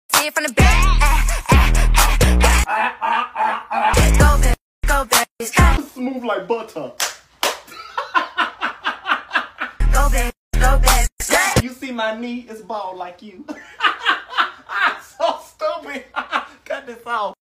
some more goofy sounds